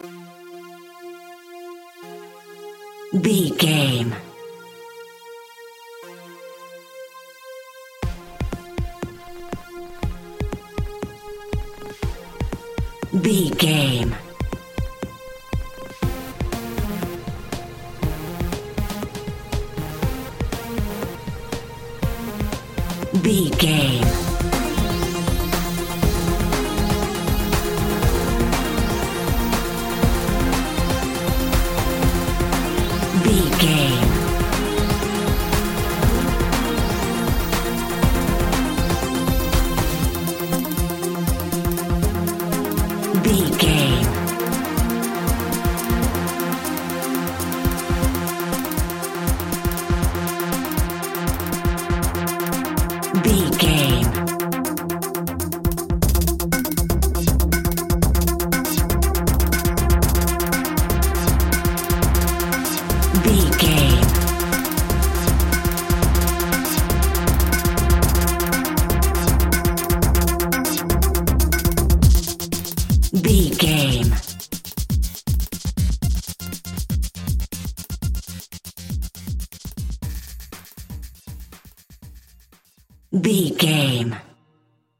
Aeolian/Minor
energetic
hypnotic
frantic
drum machine
synthesiser
house
techno
electro
synth drums
synth bass